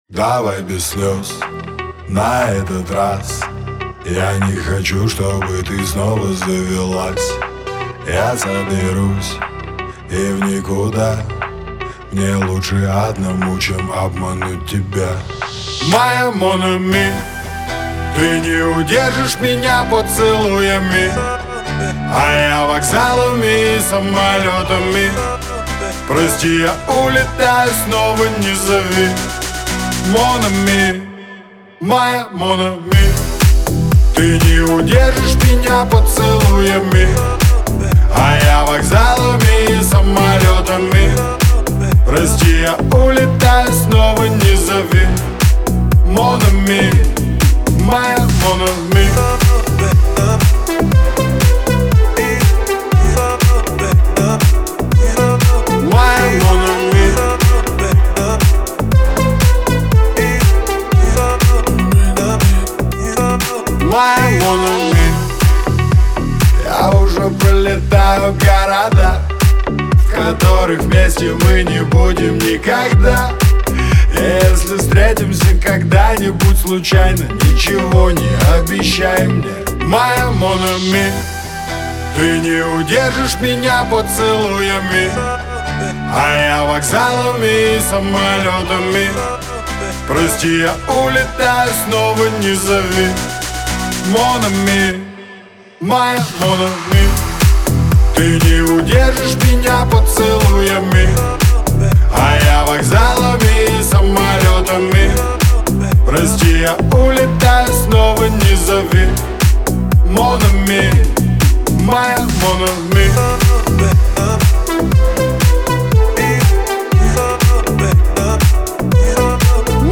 эстрада , ХАУС-РЭП , дуэт